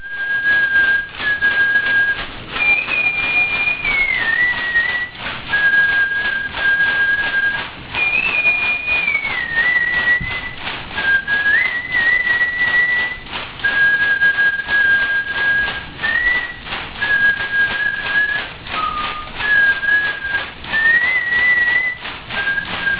звук с ритуала ayahuasca